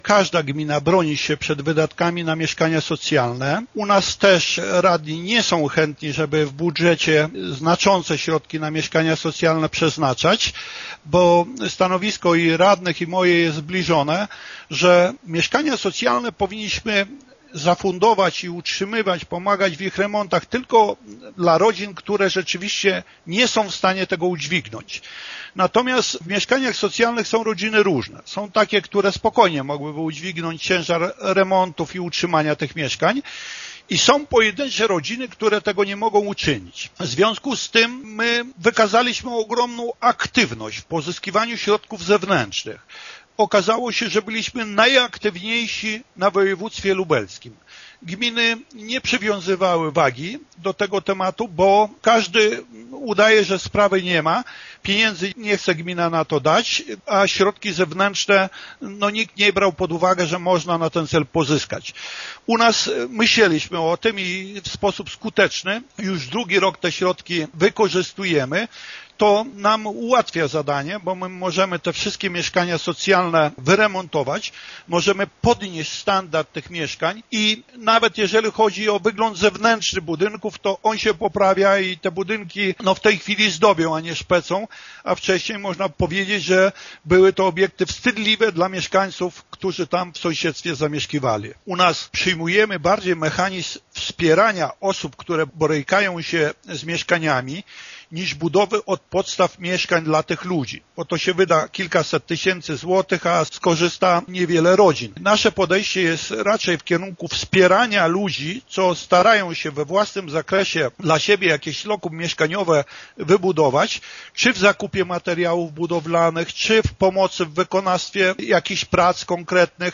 Burmistrz Dziura potwierdza, że wiele gmin w Polsce niechętnie inwestuje w budownictwo socjalne, ale – jak podkreśla - gmina Józefów stara się to robić oszczędnie i racjonalnie: